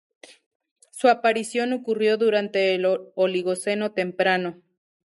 Pronounced as (IPA) /temˈpɾano/